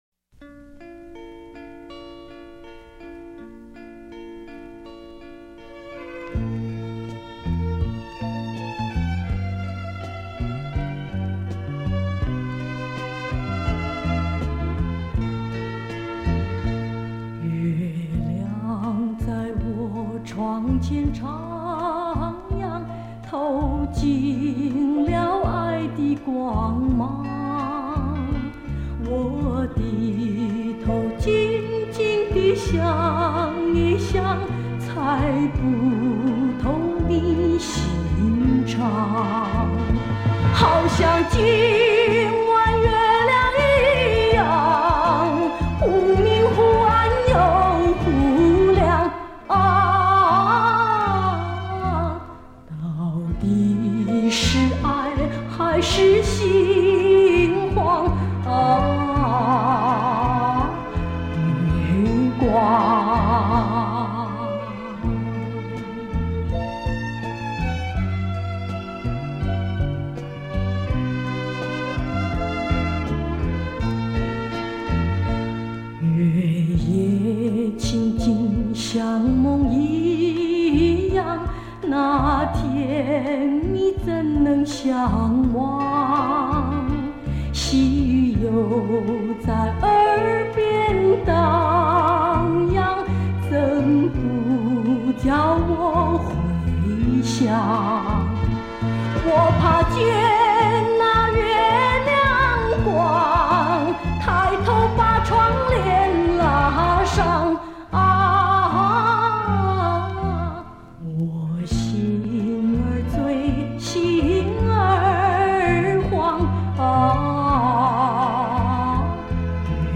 本系列采用近三十年间，最值得珍藏之原唱者母带所录制，弥足珍贵！